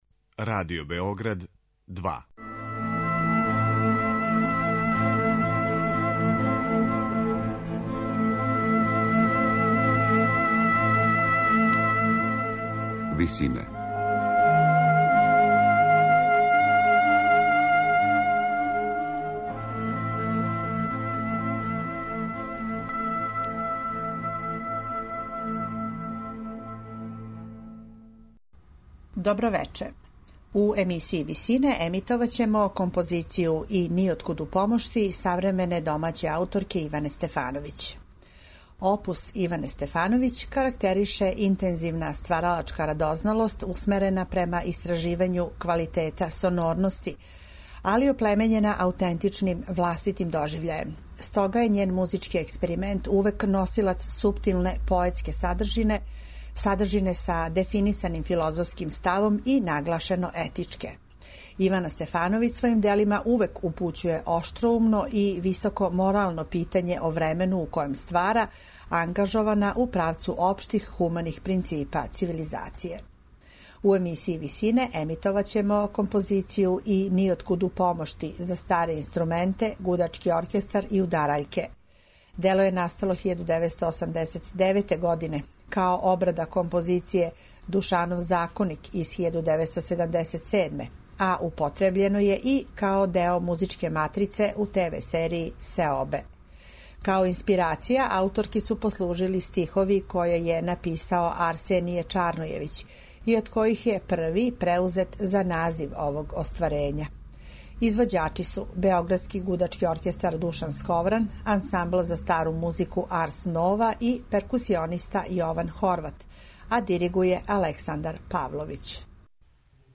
за старе инструменте, гудачки оркестар и удараљке.